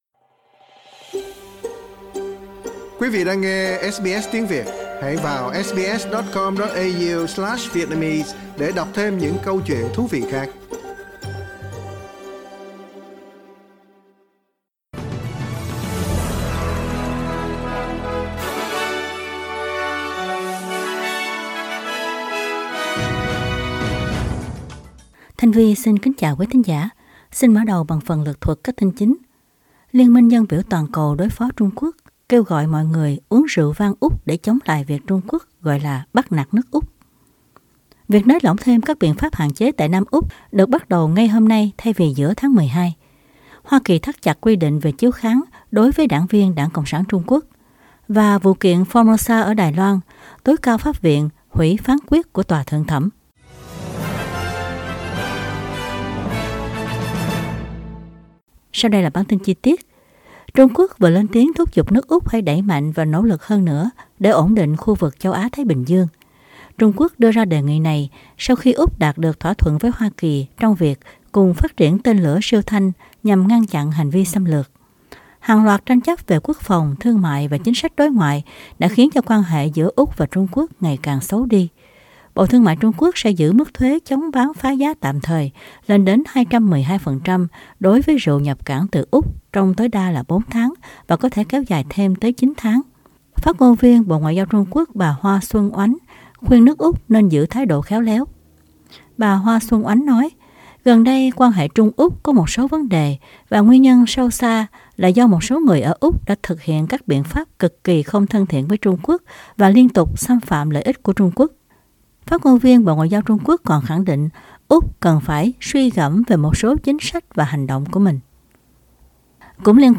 Bản tin chính trong ngày của SBS Radio.
Vietnamese news bulletin Source: Getty